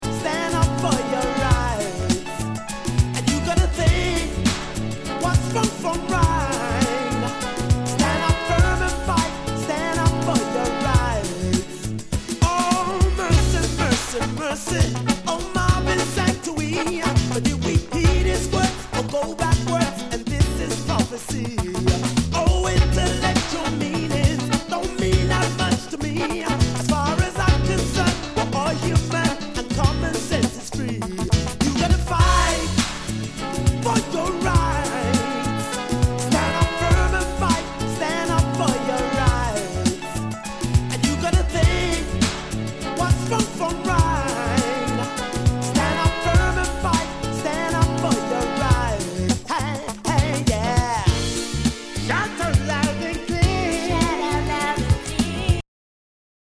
SOUL/FUNK/DISCO